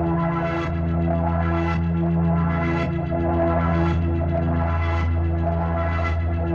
Index of /musicradar/dystopian-drone-samples/Tempo Loops/110bpm
DD_TempoDroneE_110-E.wav